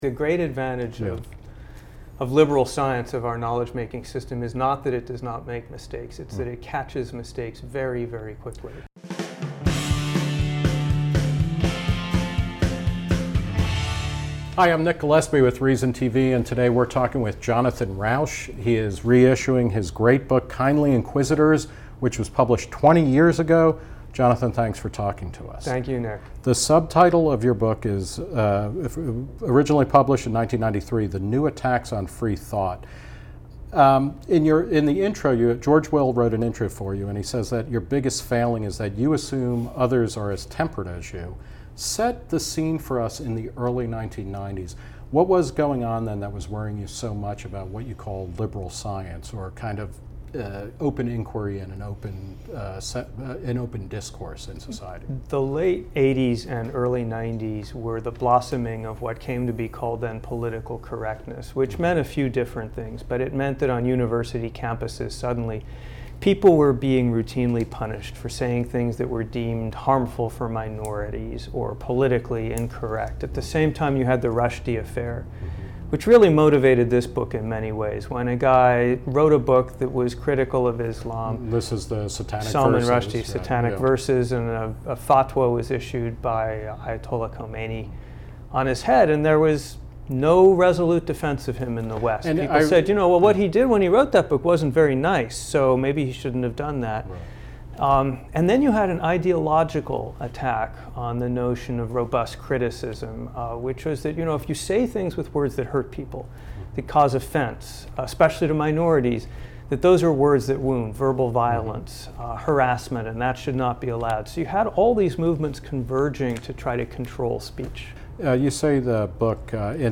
Nick Gillespie sat down with Rauch to discuss why free speech cannot and should not be abridged, even when it causes pain and discomfort.